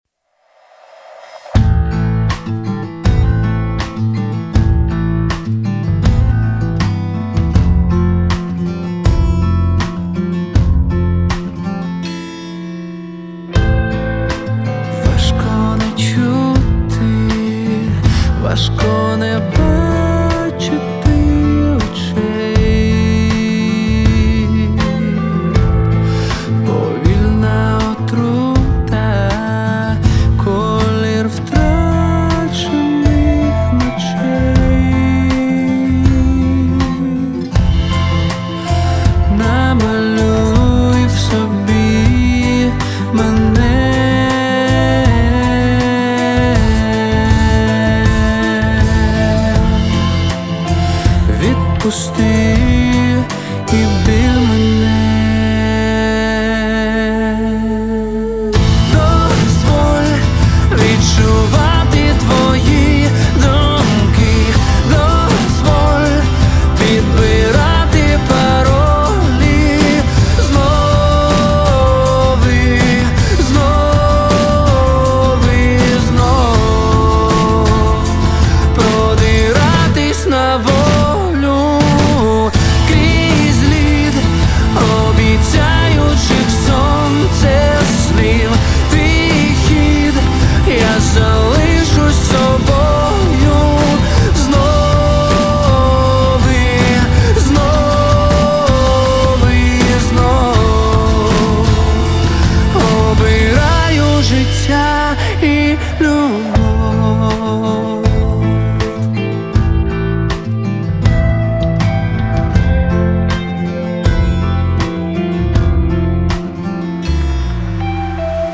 [preRelease] pop
свожу тут ребятам трек, что скажите? есть проблемы по миксу ? что и как можно улучшить? барабаны специально засраны декапитатором и слейтовой пленкой под референс